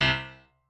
piano4_25.ogg